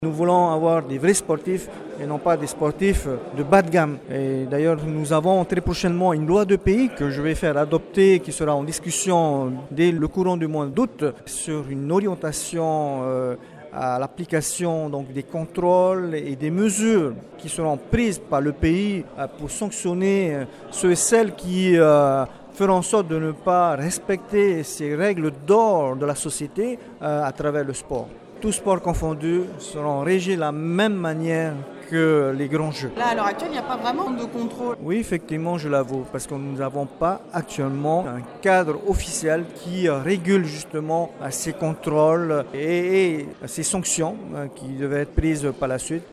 Une semaine après son retour des Jeux du Pacifique en Papouasie Nouvelle-Guinée, le ministre des Sports, René Temeharo, s’est exprimé pour Radio 1 sur la lutte contre le dopage. Le ministre défendra le mois prochain à l’assemblée deux projets de loi de Pays visant à sanctionner les athlètes polynésiens utilisant des produits dopants.